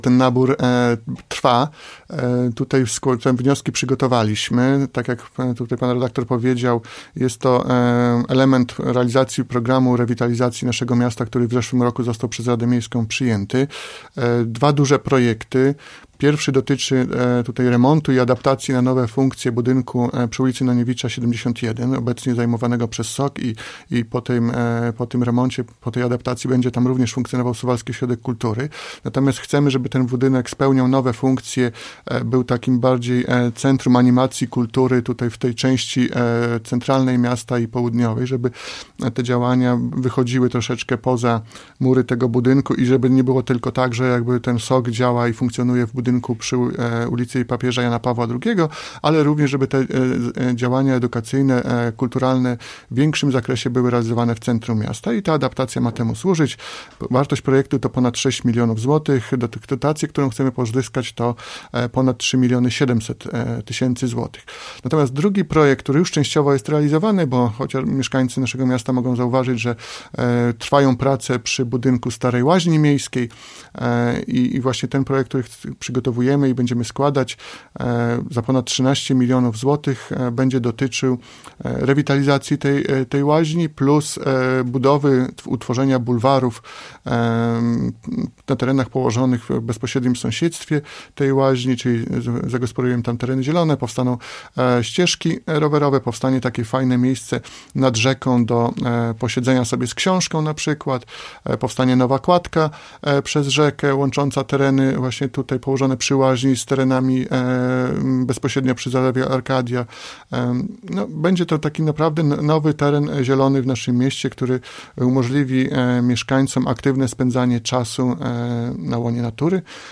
O szczegółach projektów mówił w piątek (30.03) w Radiu 5 Łukasz Kurzyna, Zastępca Prezydenta Suwałk.